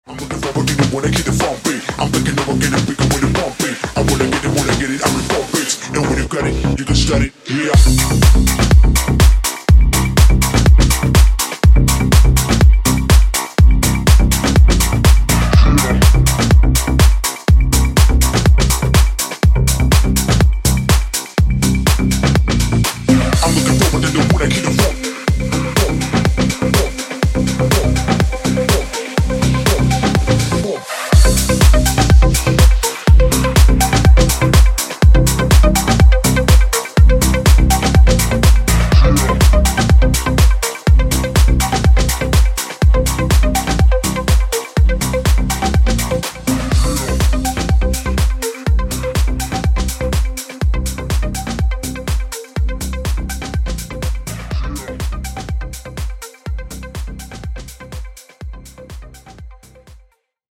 • Качество: 320, Stereo
басы
качающие
electro house
G-House
цикличные
Качёвый G-house